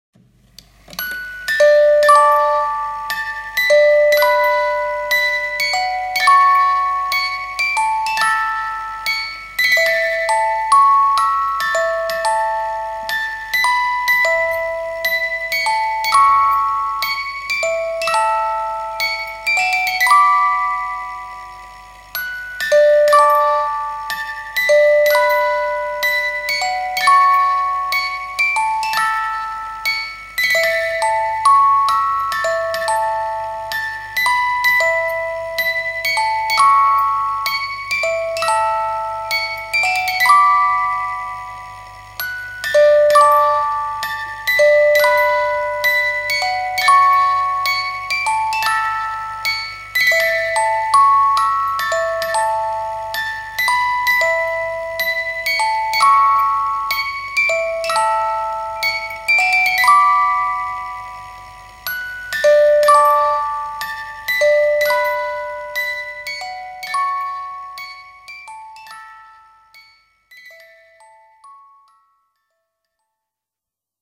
Del primer punto no vamos a tratar, pues excede los objetivos de estas lneas, ms all de resaltar la correcta toma de sonido que provoca que casi parezca que tenemos las cautivadoras cajitas musicales en la propia sala donde la msica se reproduce, incluyendo tambin, cuando sucede, los ruidos procedentes de los propios engranajes que posibilitan la creacin del sonido.
Escuchar], del maestro mexicano Agustn Lara, tema compuesto en 1932 (homenaje a una ciudad donde, por cierto, Franco le regal una casa al inspirado compositor, en agradecimiento) y reproducido aqu por un organillo. Este instrumento, al principio de mesa y dotado de lengetas, en el que una manivela mova rollos de papel codificado, fue inventado hacia 1861 en Francia, y perfeccionado por escoceses y norteamericanos, pero con el tiempo llegara a convertirse en el icono sonoro de Madrid, emblema de la msica castiza.